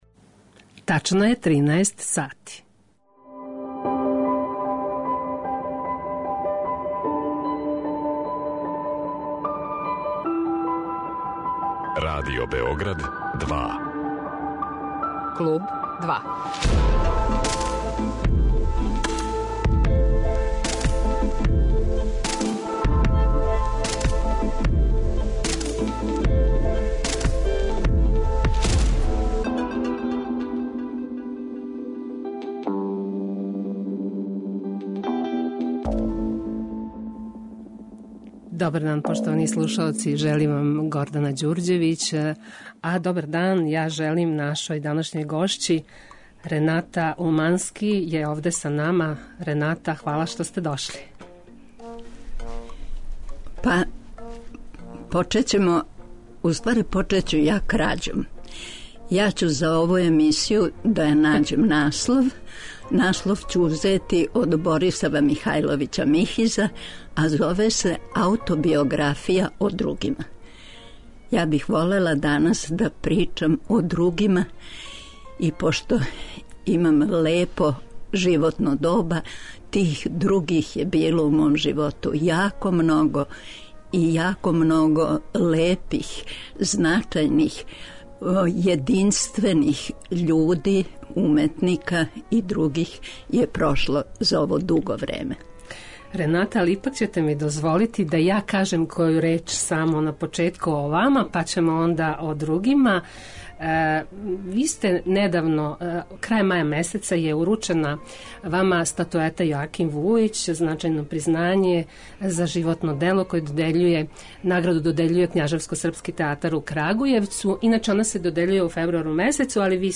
Гост 'Клуба 2' је глумица Рената Улмански.